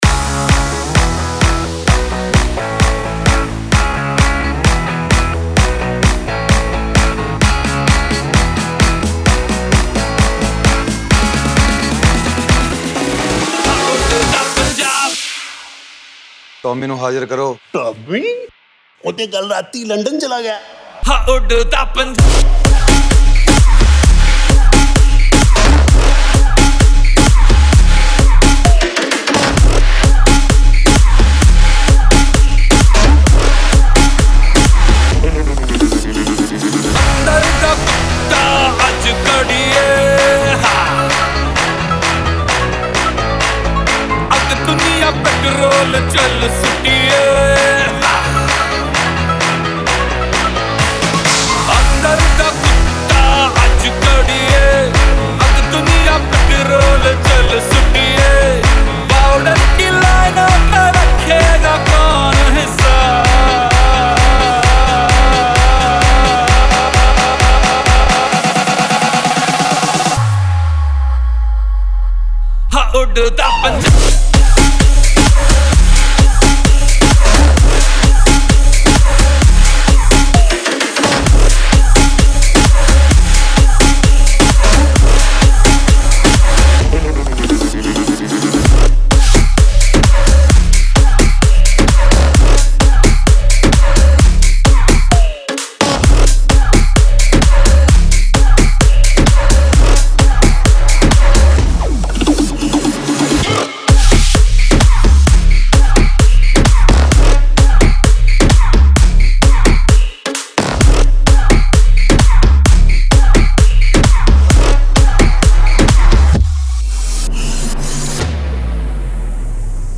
Category: Latest Dj Remix Song